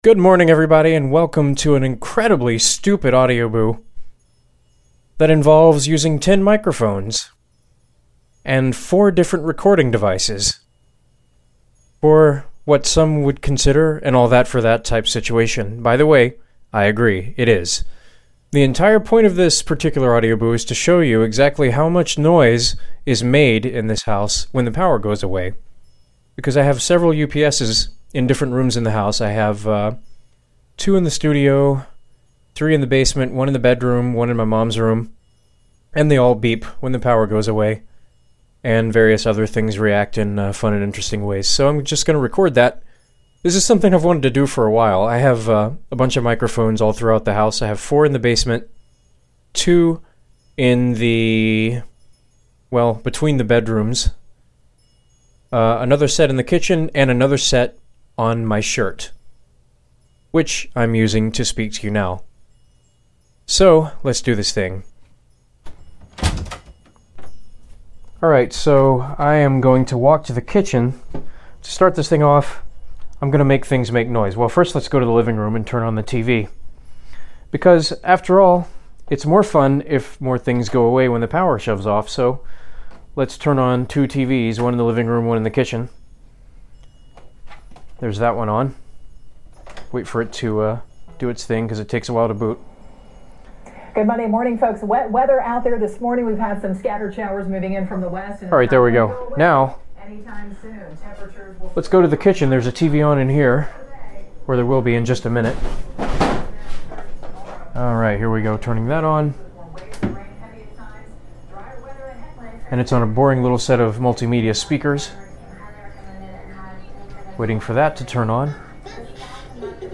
This is what happens in my house when power goes away. Recorded with ten microphones in different parts of the house: (4x Cad M179, Audio Technica AT-822 X/Y single-point stereo, built-in mics on the Olympus LS14 recorder, and an iPhone4 hosting the Tascam IXJ2 with a set of Sound Professionals MS-BMC3 omni-directional mics.)